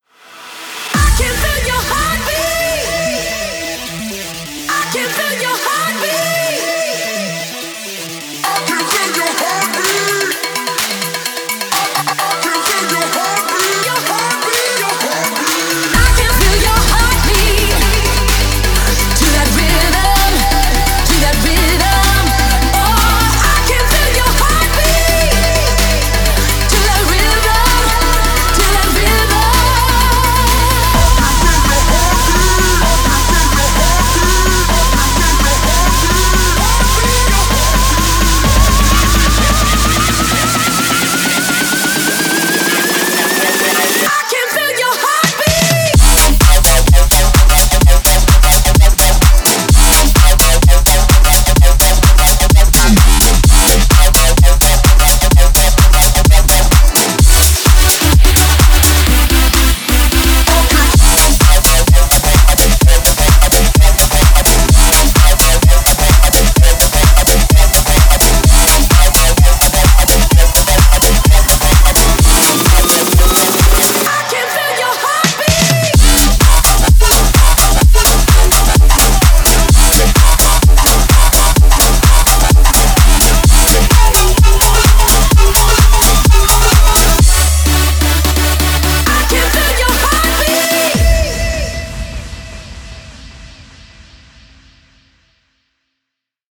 BPM128
Audio QualityPerfect (High Quality)
CommentsA tech chart for a 128 bpm microswing electro song?